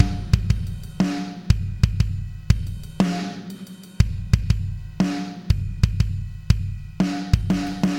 颤动的、Lofi的复古大鼓
描述：颤动的、松散的复古鼓声
Tag: 120 bpm Rock Loops Drum Loops 1.35 MB wav Key : Unknown